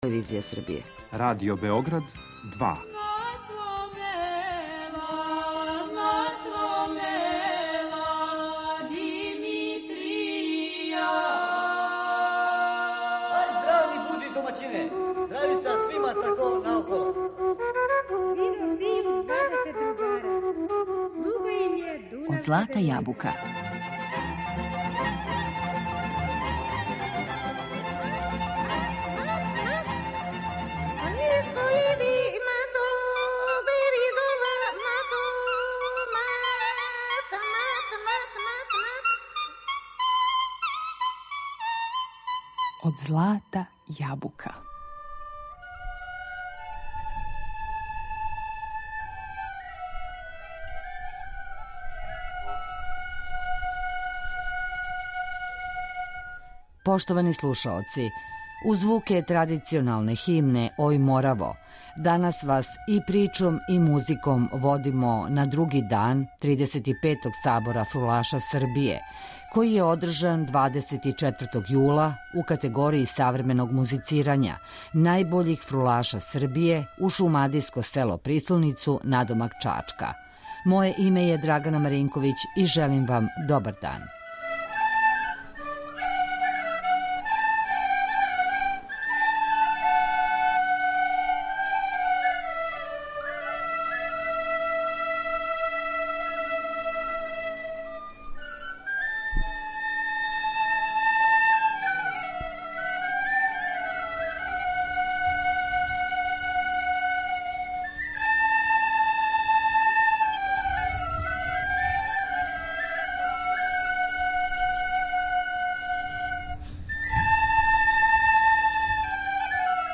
Фрула
Данас причом и музиком путујемо на друго такмичарско вече које је одржано 24 јула на Сабору фрулаша Србије " Ој Мораво" у Прислоници код Чачка. Представићемо награђене дуете и солисте у категорији савременог музицирања.